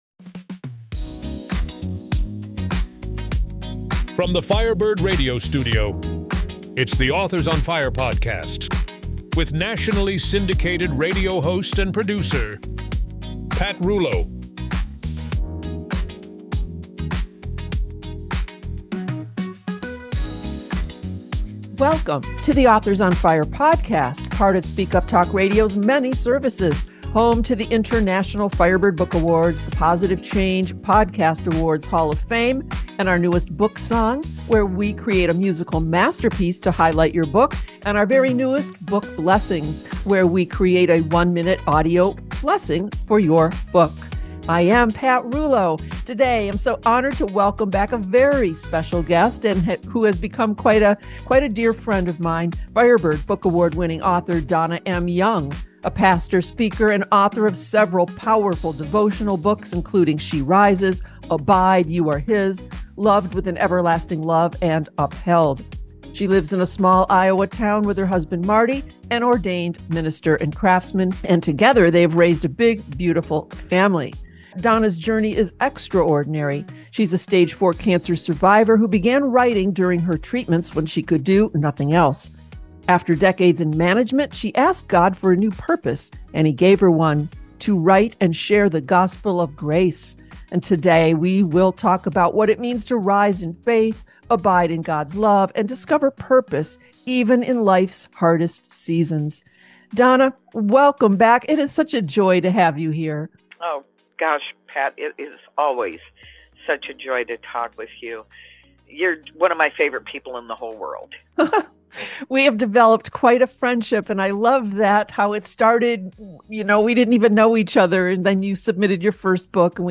Firebird Book Award Winner Author Interview